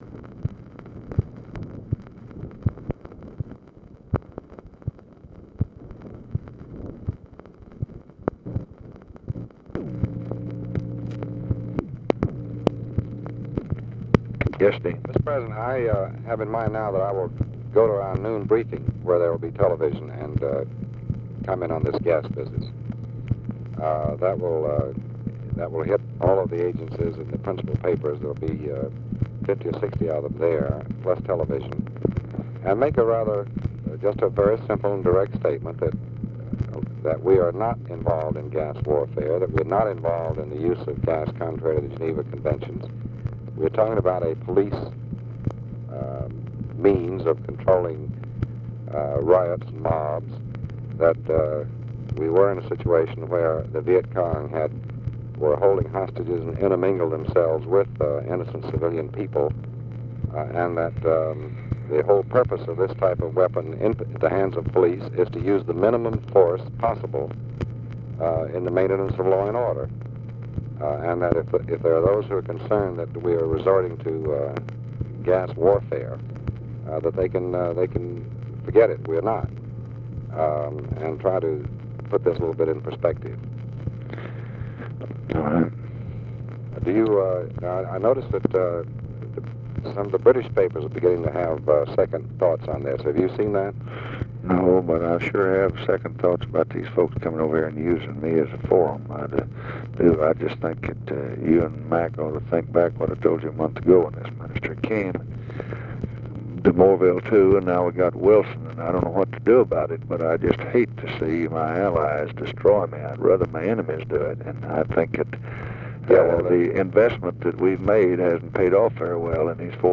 Conversation with DEAN RUSK, March 24, 1965
Secret White House Tapes